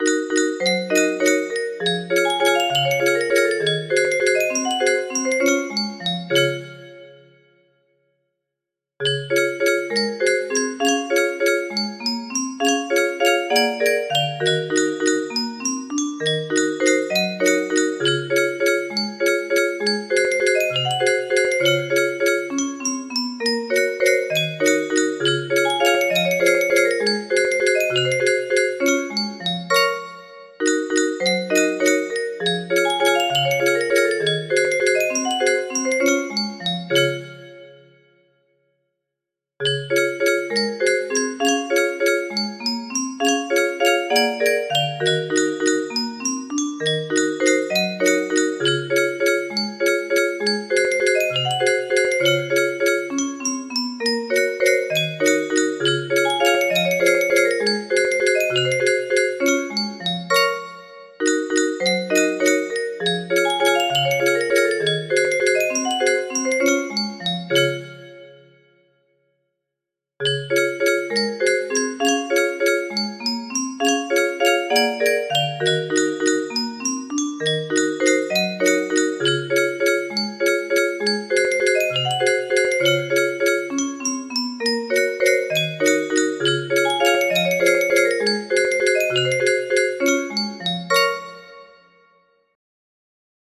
Степь да степь кругом music box melody
Full range 60